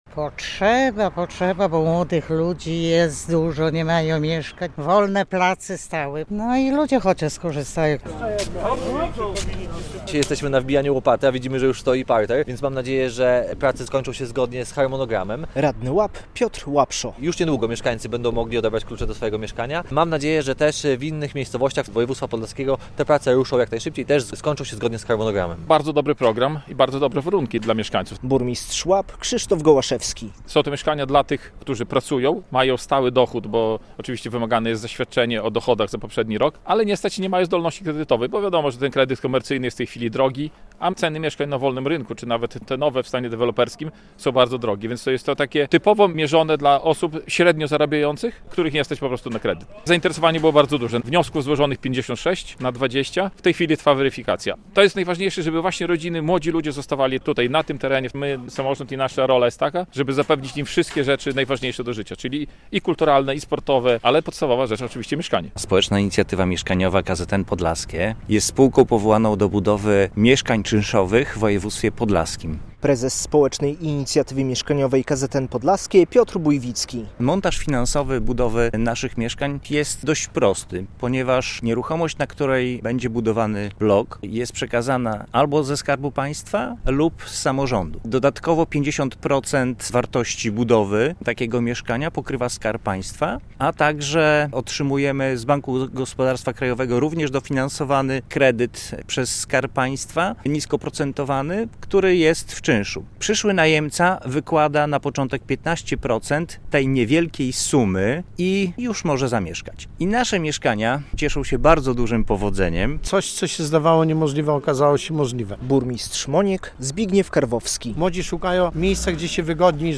Pierwsze bloki na wynajem - relacja